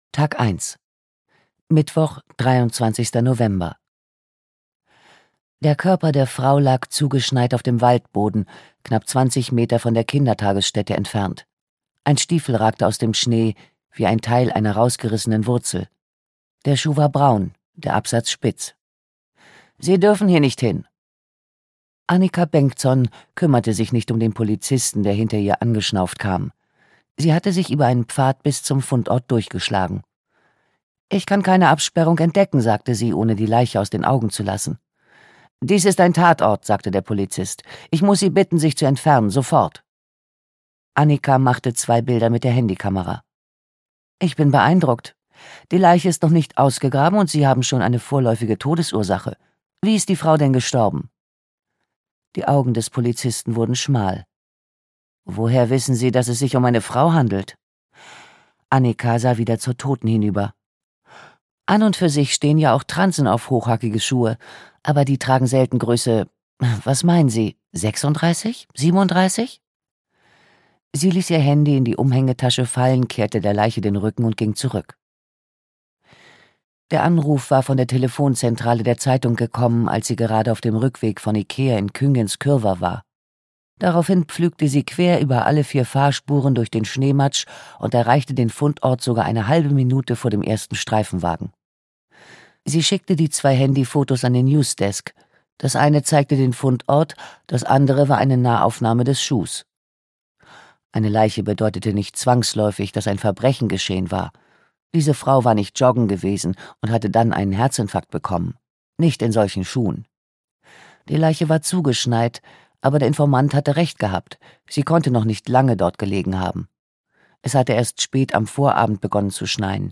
Weißer Tod - Liza Marklund - Hörbuch